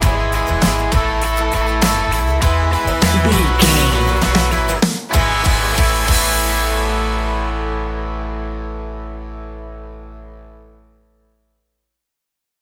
Aeolian/Minor
electric guitar
acoustic guitar
bass guitar
drums